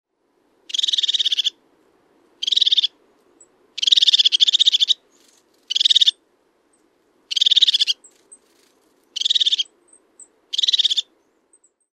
Ja näin viehättävältä kuulostaa, kun töyhtötiaisella on asiaa: Sii sii tsirririririt.
toyhtotiainen_varotus.mp3